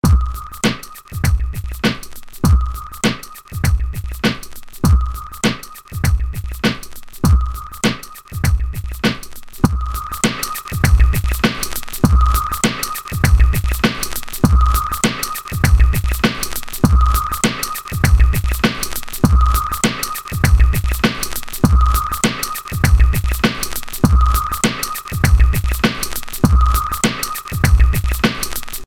Und weiter geht’s: Spectrasonics Stylus RMX, ein Loop aus der Expansion-Library Big Bad Beats („Jack Attack“), FG-Bomber setzt bei Takt 5 ein, Modus Fat, Drive und Intensity im Rechtsanschlag (maximaler Effekt):
Jetzt haben wir genau das, was ich oben beschrieben habe: Der Beat fängt durch FG-Bomber an zu atmen bzw. zu pumpen, was bei diesem Material durchaus gewünscht sein kann. Außerdem treten die Details, das „Knistern“ in den Höhen herrlich hervor.
Insgesamt klingt der Beat deutlich plastischer und erfährt eine spürbare Aufwertung.